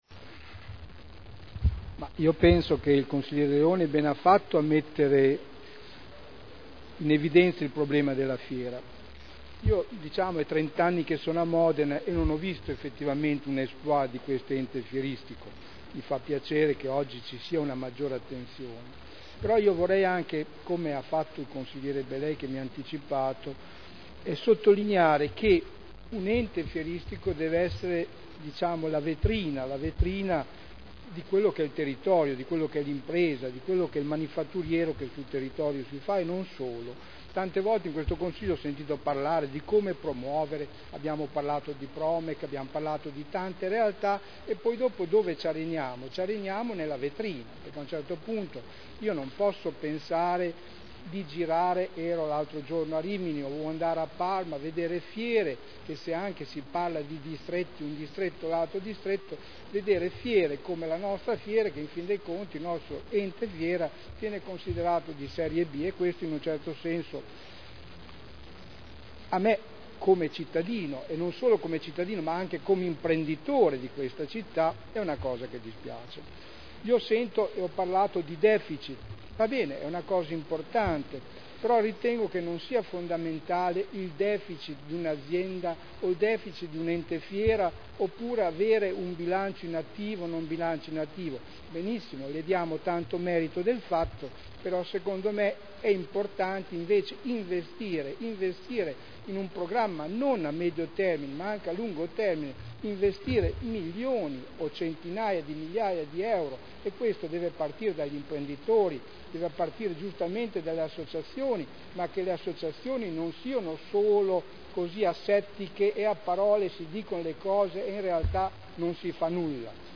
Sergio Celloni — Sito Audio Consiglio Comunale